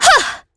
Isaiah-Vox_Attack1.wav